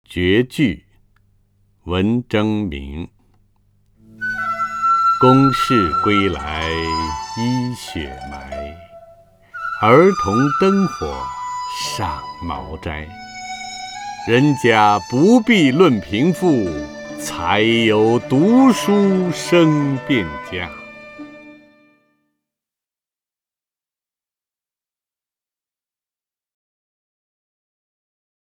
陈铎朗诵：《绝句·公事归来衣雪埋》(（明）文征明)　/ （明）文征明
名家朗诵欣赏 陈铎 目录